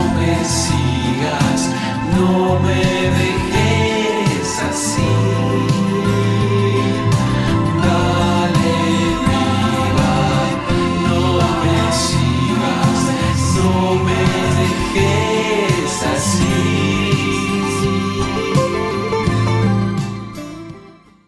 Grabado en casa.
Guitarras